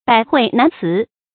百喙難辭 注音： ㄅㄞˇ ㄏㄨㄟˋ ㄣㄢˊ ㄘㄧˊ 讀音讀法： 意思解釋： 喙：嘴，借指人的嘴。指縱有眾多百口也很難解釋。